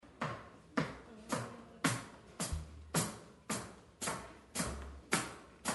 Live :: Location :: Mobile :: Recording
There was a large cast of musicians - drums, percussion, guitar, bass, 2 keys, flute, violin, five vocals - it was quite a challenge to record (I also set up and ran sound for the event).
Jazz Singer